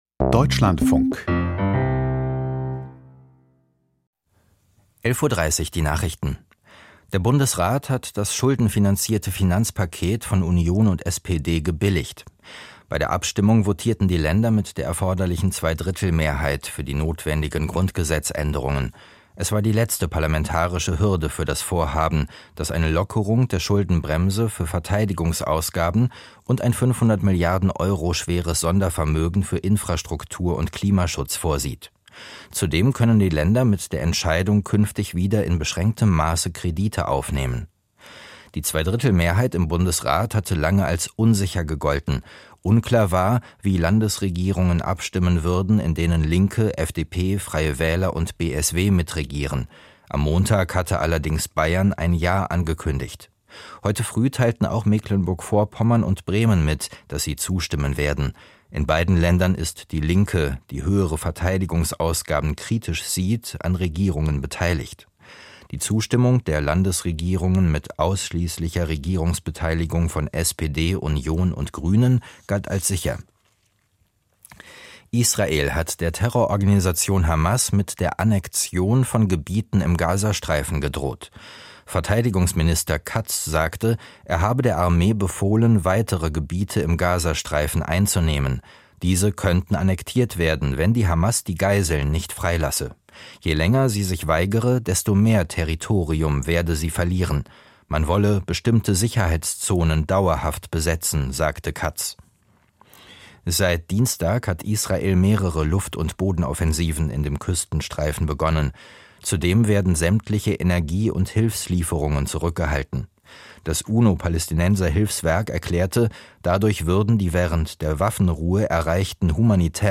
Die Deutschlandfunk-Nachrichten vom 21.03.2025, 11:30 Uhr